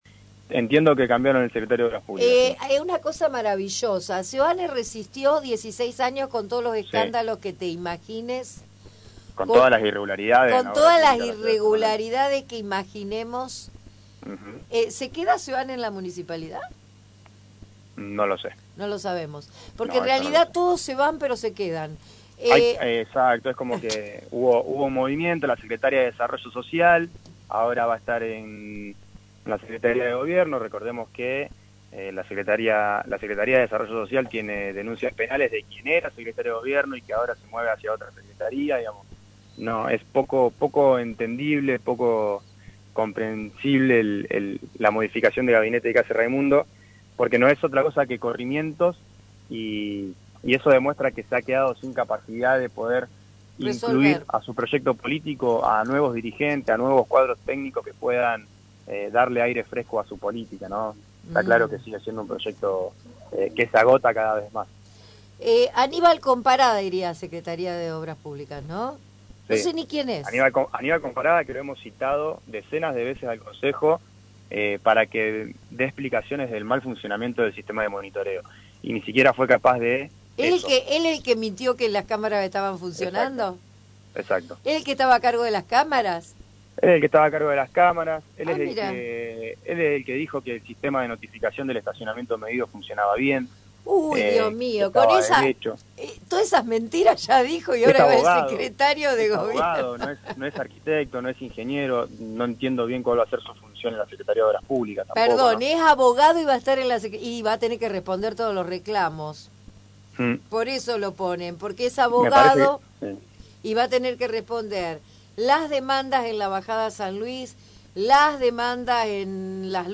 «Más de lo mismo,  cambian de lugares» dice el concejal Martín Cerdera, «un gabinete que va de mal en peor» en diálogo con «Un día de Gloria» hace un análisis de este enroque en el gabinete de Raimundo.